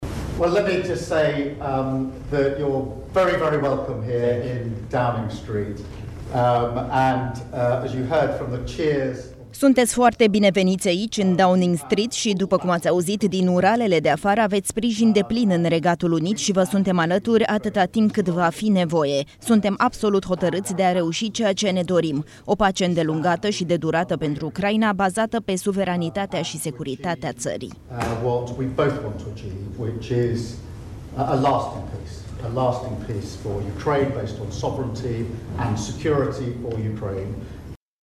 02mar-09-Kier-Starmer-sunteti-foarte-bine-veniti-aici-TRADUS.mp3